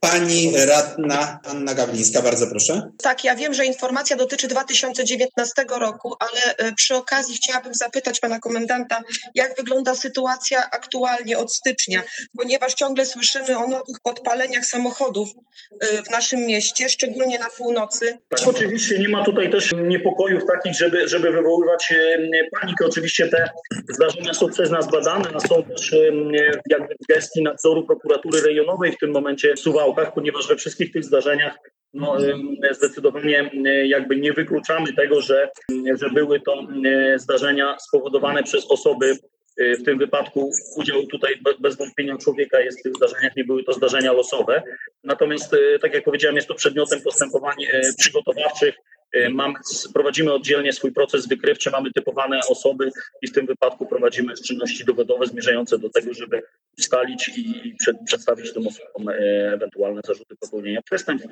– Mamy typowane osoby i prowadzimy czynności dowodowe, aby przedstawić ewentualne zarzuty – mówił radnym o sprawie podpaleń aut w Suwałkach podinspektor Bartosz Lorenc, komendant miejscowej jednostki policji. Komendant uczestniczył w sesji, bo zdawał sprawozdanie ze swojej działalności za ubiegły rok.